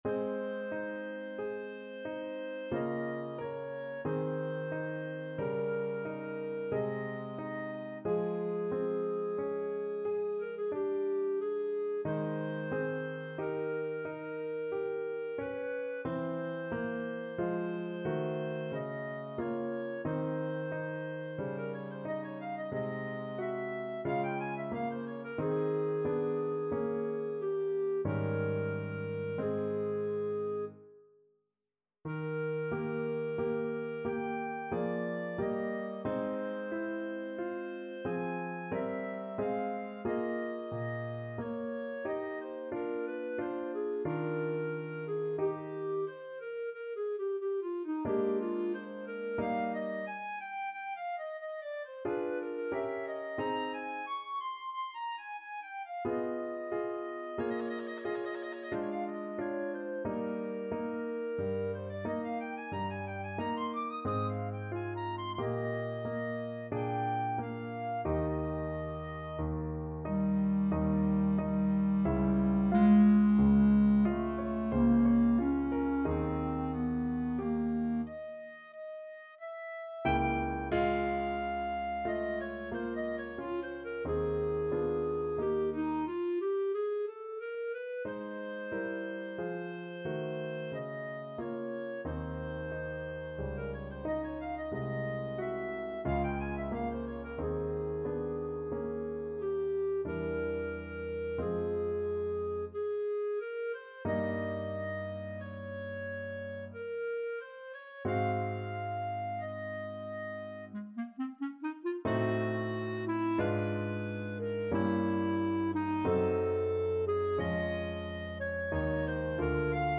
Clarinet version
D4-Eb7
3/4 (View more 3/4 Music)
Adagio =45
Classical (View more Classical Clarinet Music)